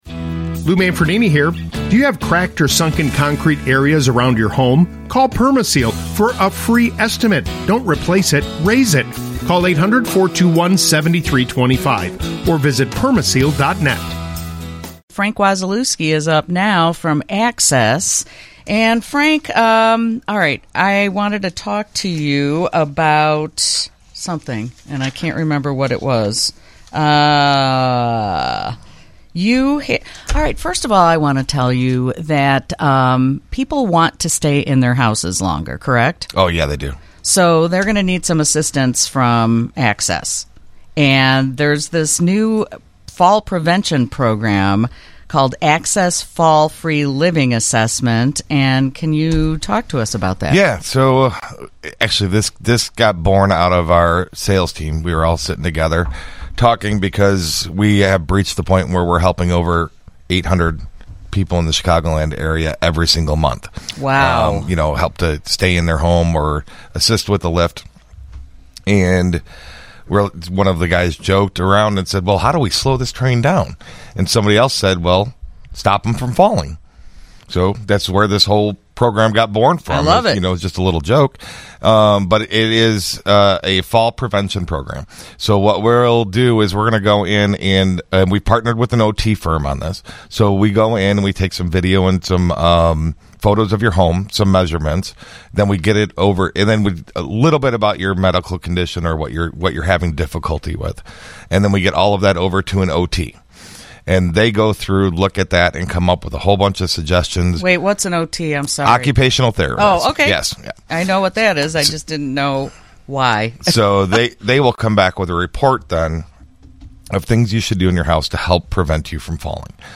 Featured on WGN Radio’s Home Sweet Home Chicago on 08/30/25